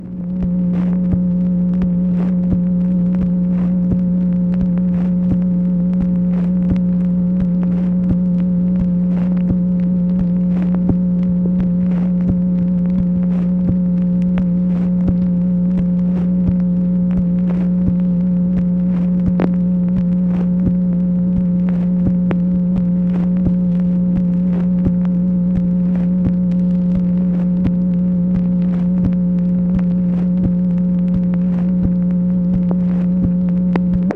MACHINE NOISE, May 3, 1966
Secret White House Tapes | Lyndon B. Johnson Presidency